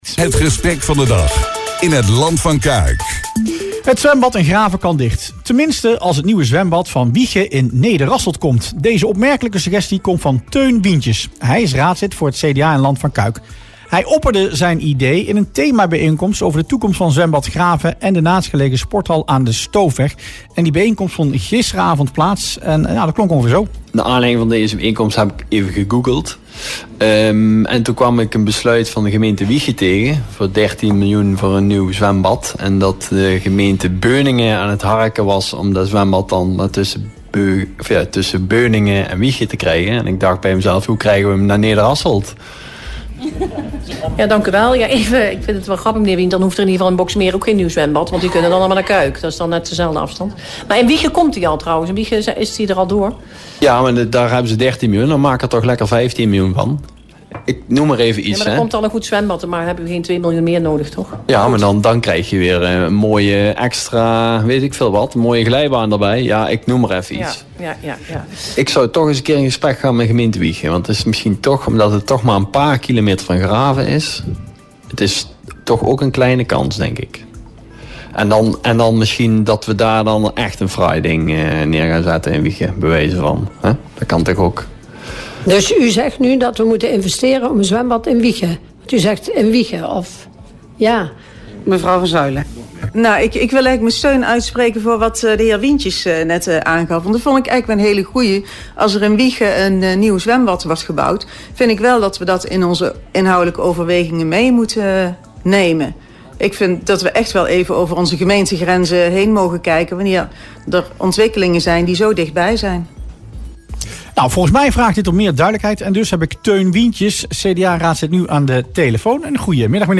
CDA-raadslid Teun Wientjes in Rustplaats Lokkant